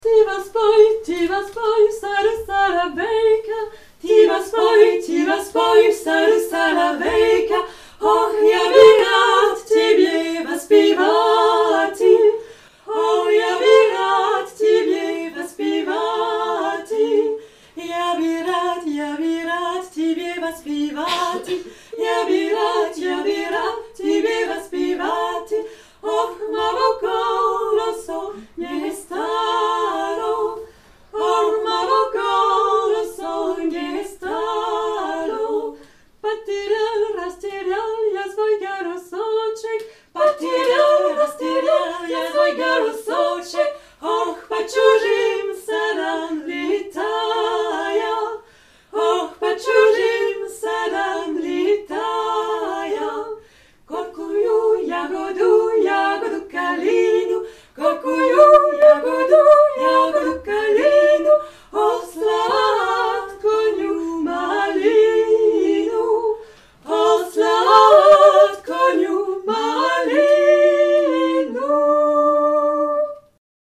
Concert – Duo de femmes a capela
C’est un duo de femmes a capella, interprétant des mélodies des Balkans et des chansons populaires tantôt gaies, tantôt nostalgiques.
C’est une complicité des timbres, un goût pour aller chanter là où on ne les attend pas (en extérieur, en nature) et une recherche permanente du mariage des voix entre elles et l’acoustique du lieu.
Ce récital est composé de chants populaires de Hongrie et de Russie.
On reste dans la musique folklorique.